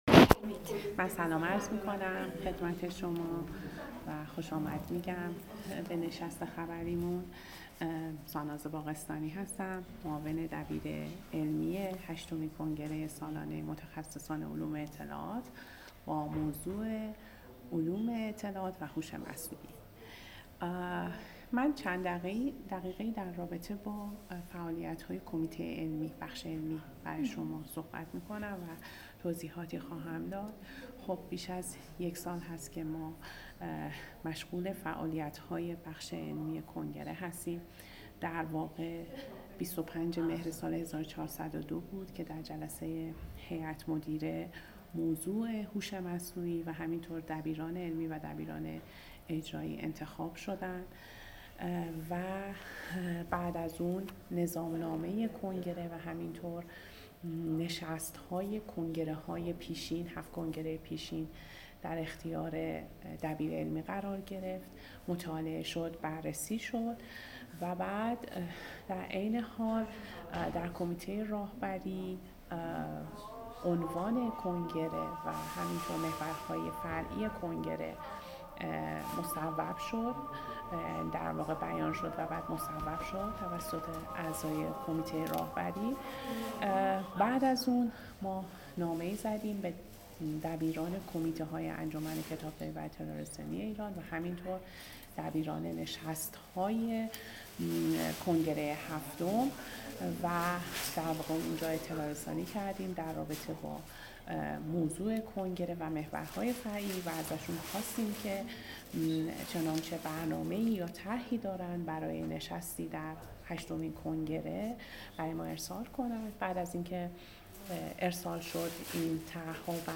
عطنا- نشست هشتمین کنگره سالانه‌ی علوم اطلاعات در روز یکشنبه ۱۳ آبان ۱۴۰۳ در تالار اندیشگاه مرکز همایش های بین‌المللی کتابخانه‌ی ملی با هدف تشریح برنامه‌ها و اهداف آن برگزار شد.